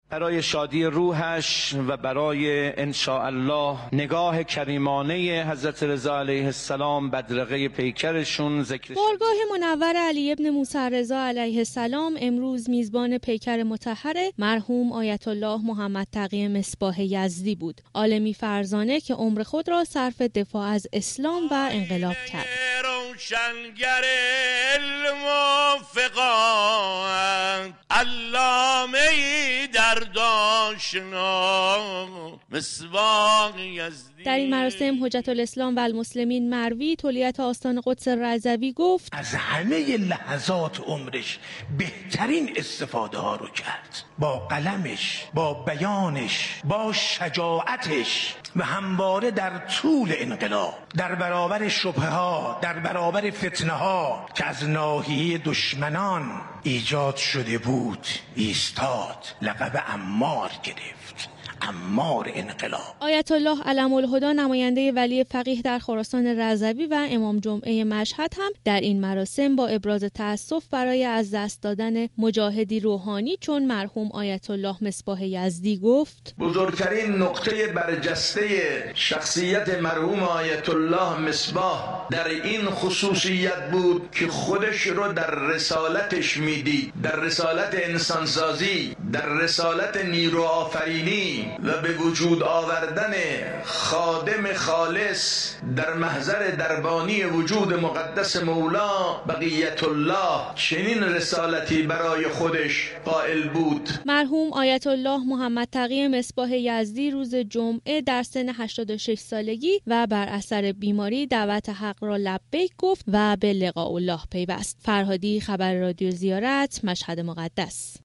گزارش خبرنگار رادیو زیارت را در این زمینه بشنوید.